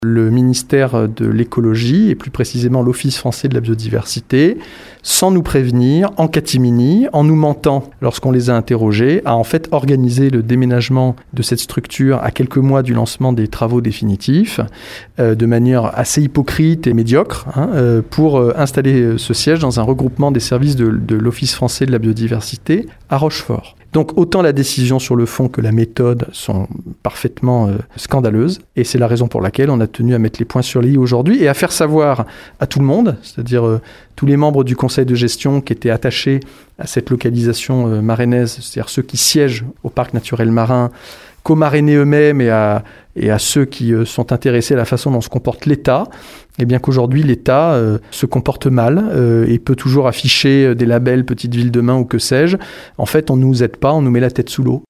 On écoute Mickaël Vallet :